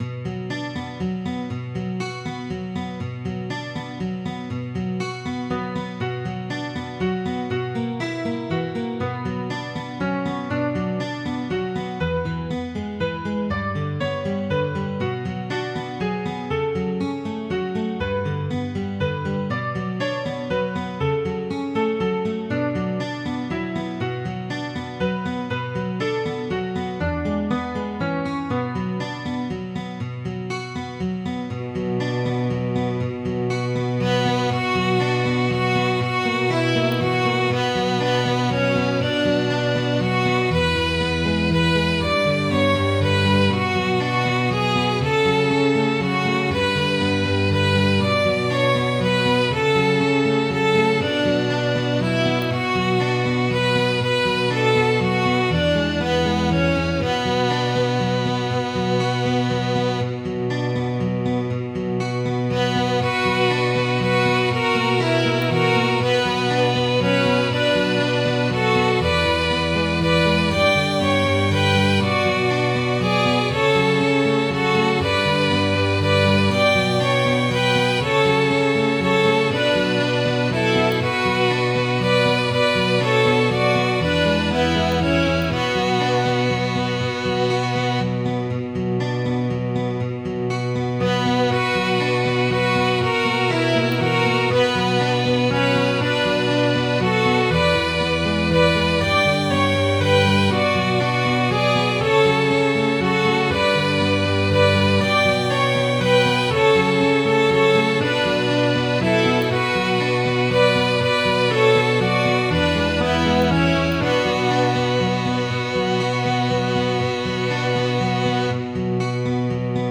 Midi File, Lyrics and Information to She's Like A Swallow